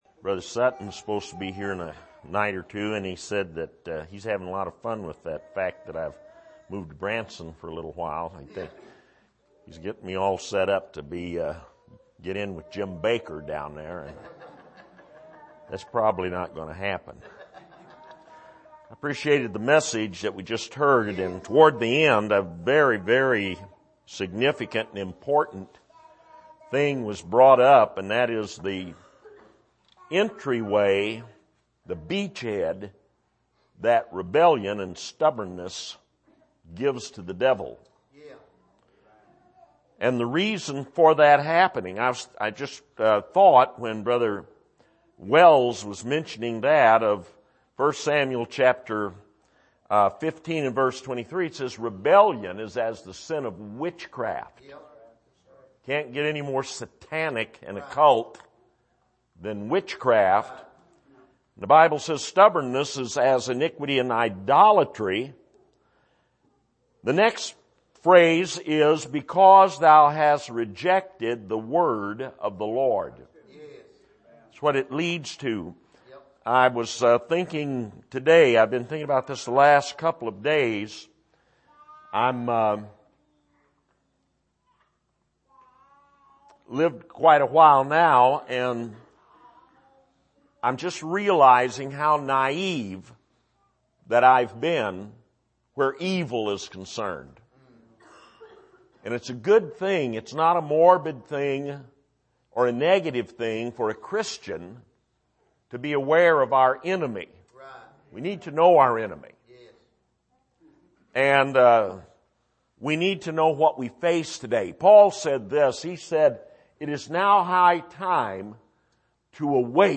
Service: Bible Conference